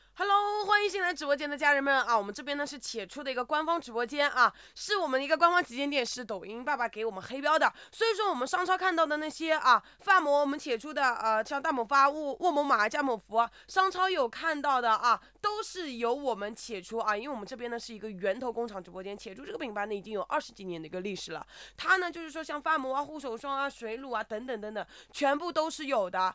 以下是三种场景下真人音色与AIGC音色的对比：
电商直播_真人录音
电商直播_真人录音_demo.wav